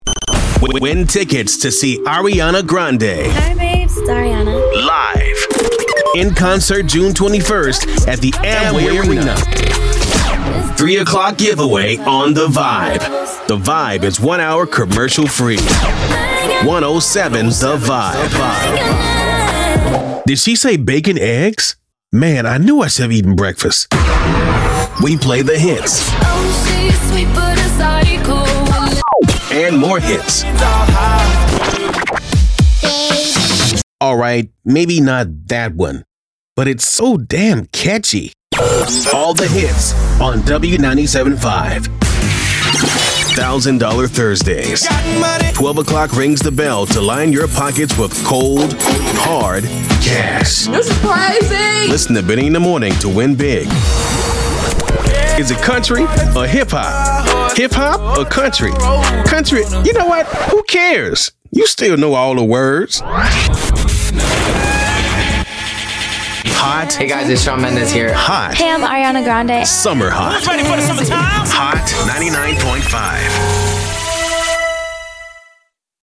Affiliates and Imaging - Radio 2